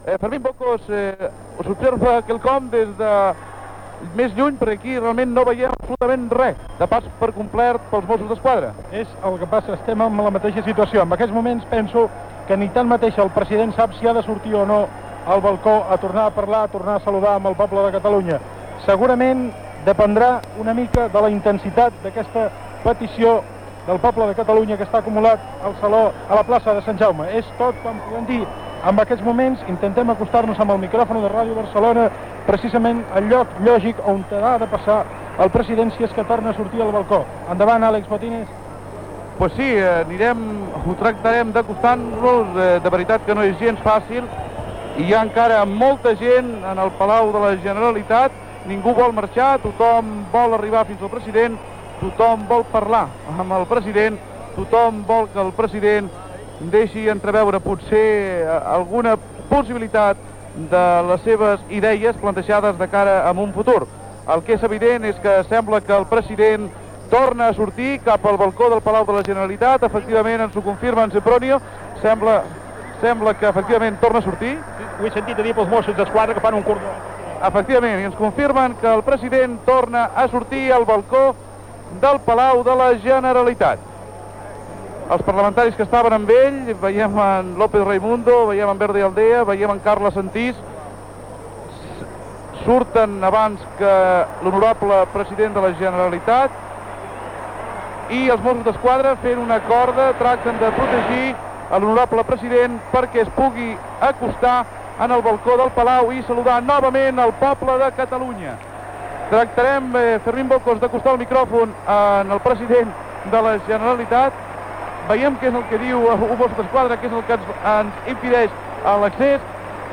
Final de la transmissió del retorn de l'exili del president de la Generalitat Josep Tarradellas a la ciutat de Barcelona. Narració des de la Plaça de Sant Jaume en el moment que Josep Tarradellas torna a sortir al balcó del Palau de la Generalitat a adreçar-se a la multitud present icantar l'Himne de Catalunya "Els segadors". Paraules de Josep Tarradellas.